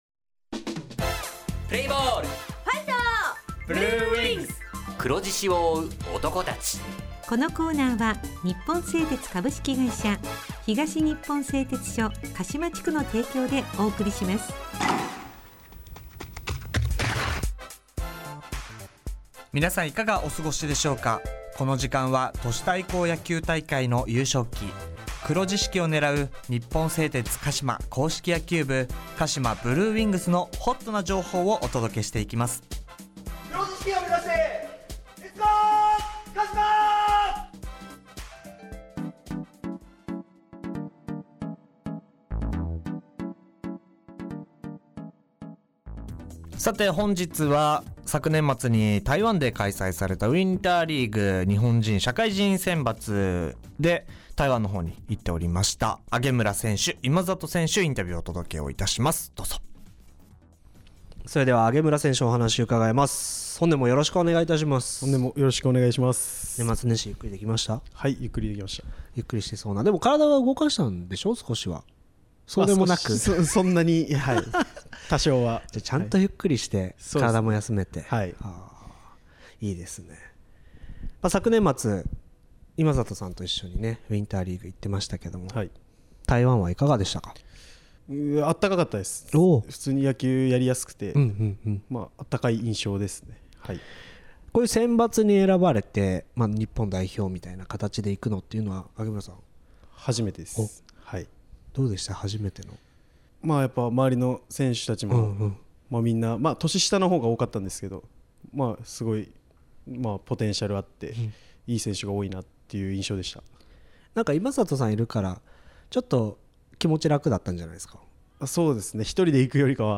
地元ＦＭ放送局「エフエムかしま」にて鹿島硬式野球部の番組放送しています。